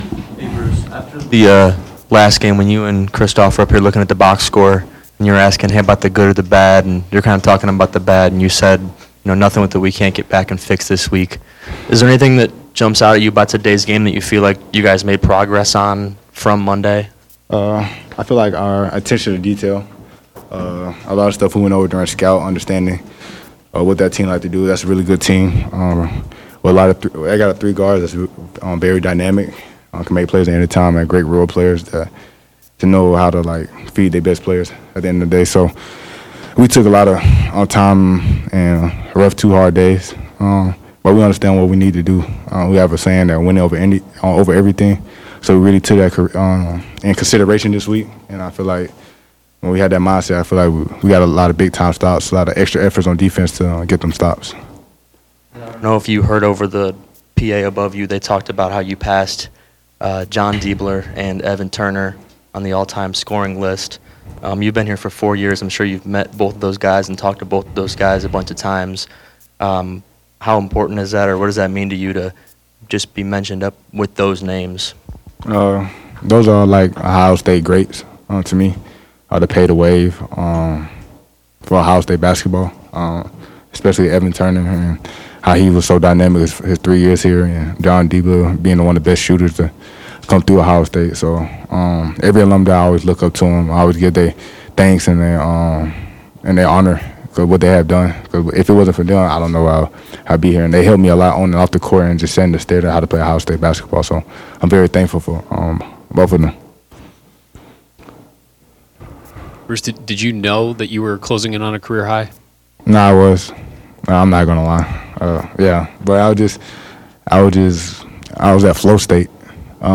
Postgame Press Conference after 94-68 win over Purdue Fort Wayne Friday November 7, 2025